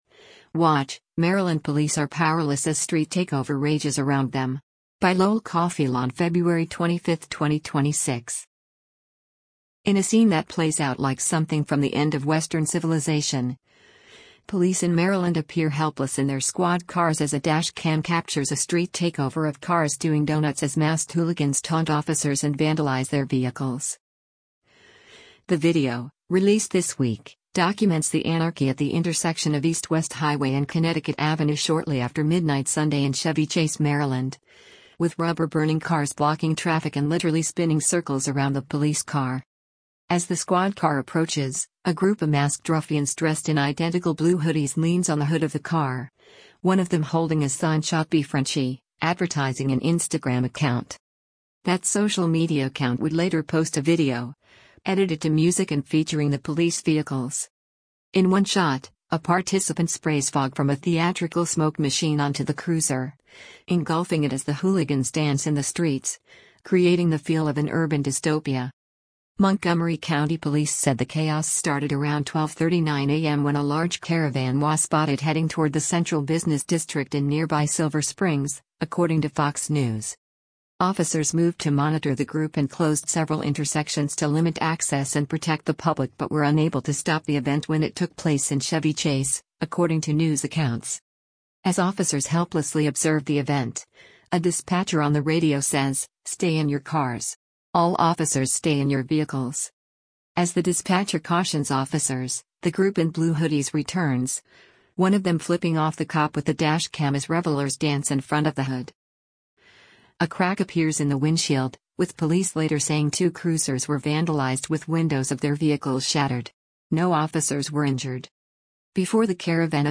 In a scene that plays out like something from the end of western civilization, police in Maryland appear helpless in their squad cars as a dash cam captures a street takeover of cars doing doughnuts as masked hooligans taunt officers and vandalize their vehicles.
The video, released this week, documents the anarchy at the intersection of East West Highway and Connecticut Avenue shortly after midnight Sunday in Chevy Chase, Maryland, with rubber-burning cars blocking traffic and literally spinning circles around the police car.
As officers helplessly observe the event, a dispatcher on the radio says, “Stay in your cars. All officers stay in your vehicles.”